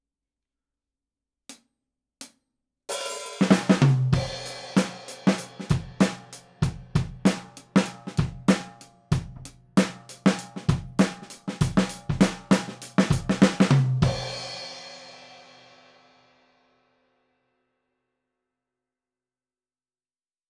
すべて、EQはしていません。
今度は、ドラムキットの上から狙ってみます！ちょうどタムの上あたりですね！
バスドラムが小さくなりましたが、
ドラム全体がバランスよく録れていますね！
ドラム-アンビ-top.wav